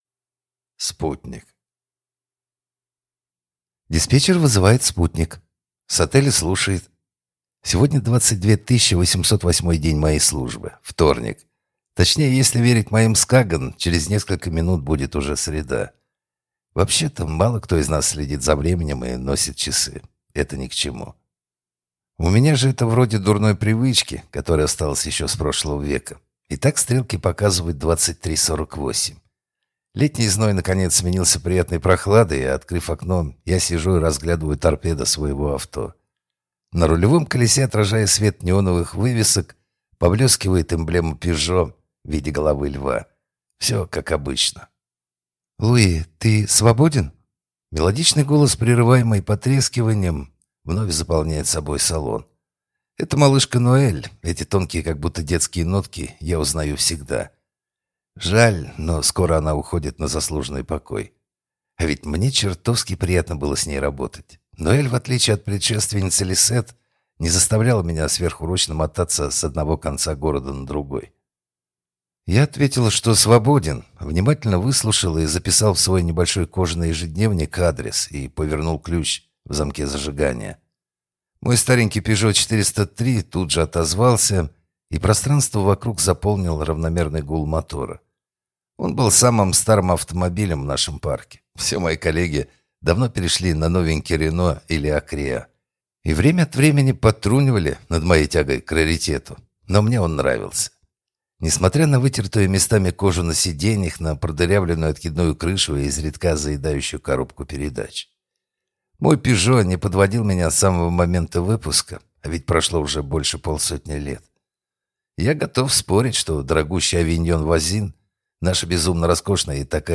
Аудиокнига Спутник | Библиотека аудиокниг